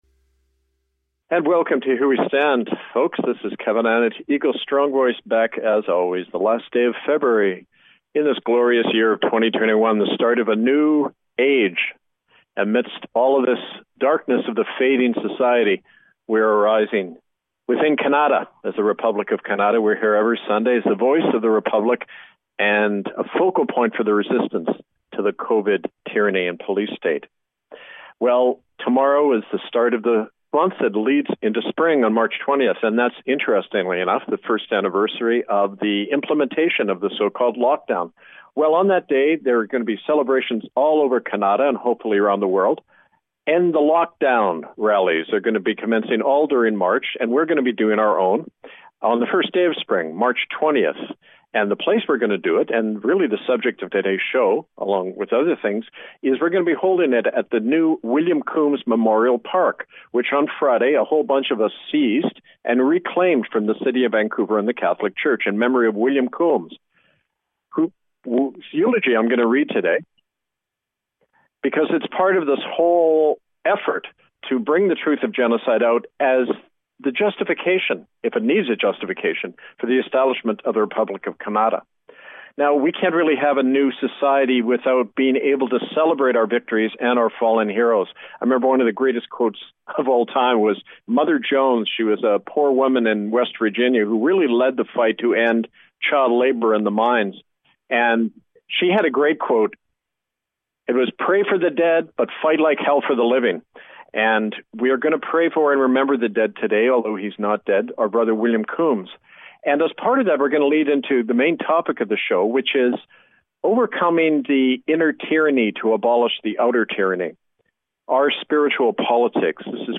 Airing a Rerun from October 25, 2020